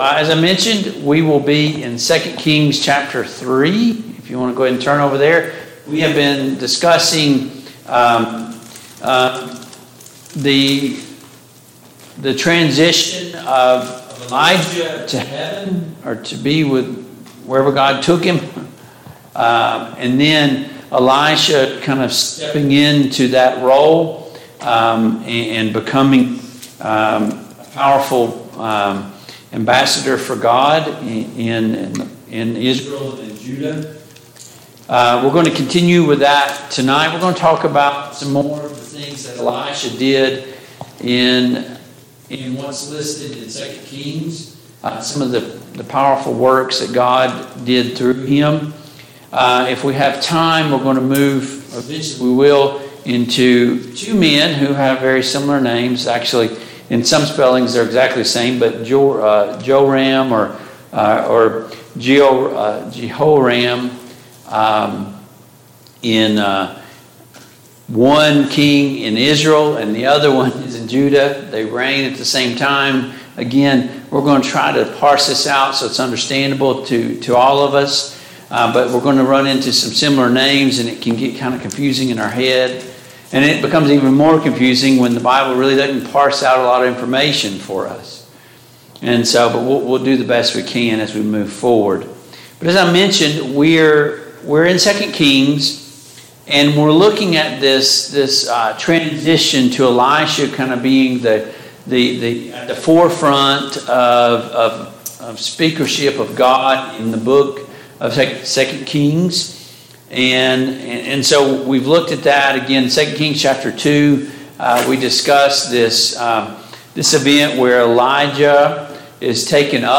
The Kings of Israel Passage: 2 Kings 2, 2 Kings 3, 2 Kings 4, 2 Kings 5, 2 Kings 6 Service Type: Mid-Week Bible Study Download Files Notes « 2.